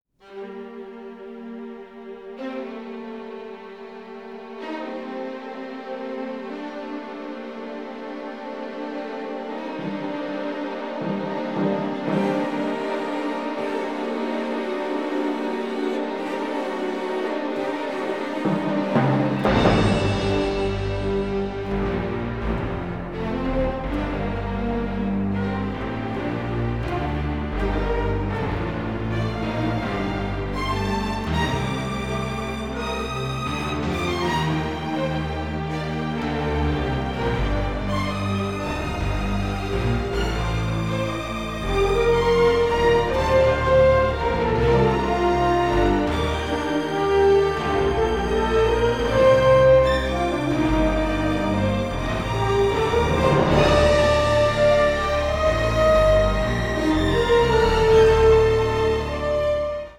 emotional, symphonic Americana score